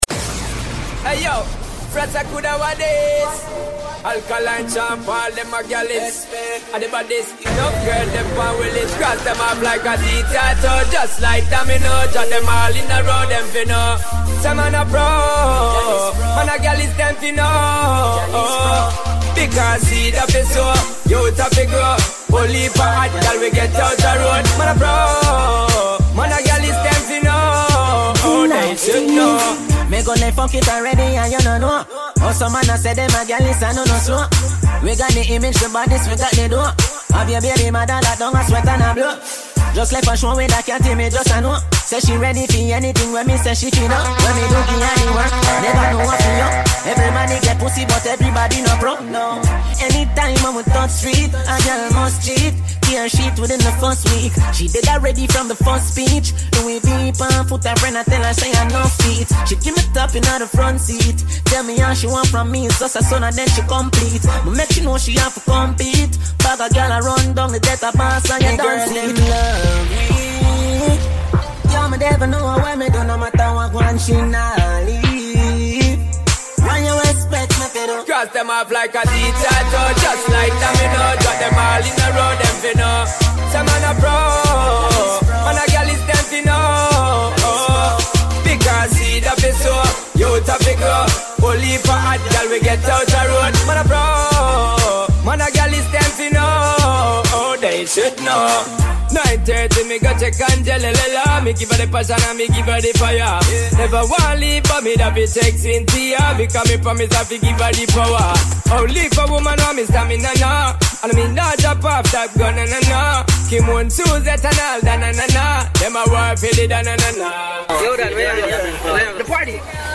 Genre: DJ Mixes.